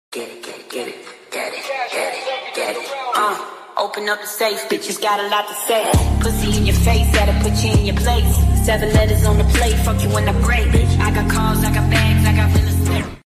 Use Headphones Best Experience 8d